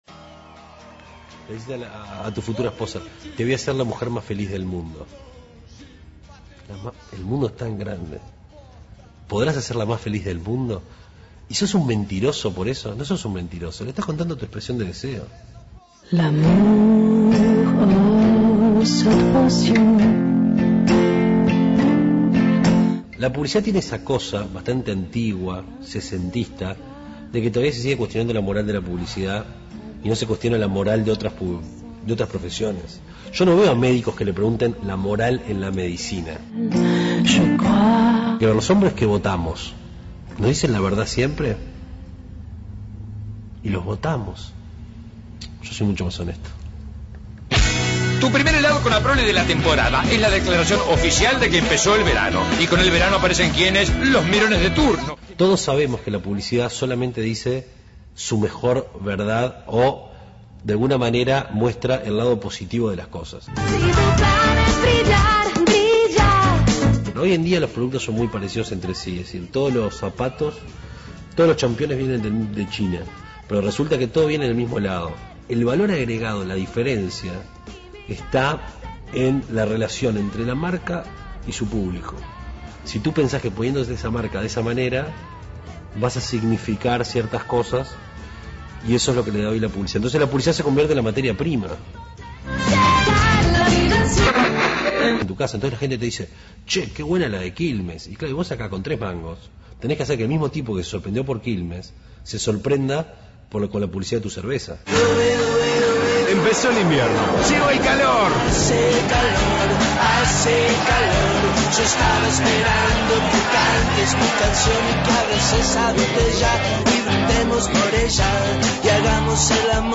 un diálogo con un creativo